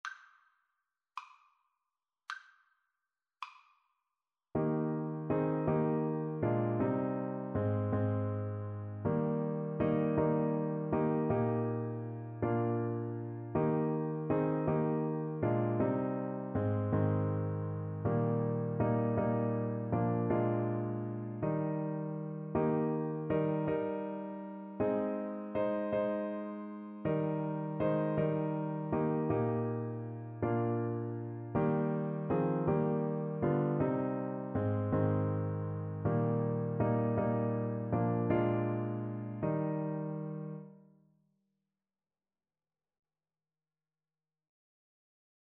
• Unlimited playalong tracks
6/4 (View more 6/4 Music)
Classical (View more Classical Saxophone Music)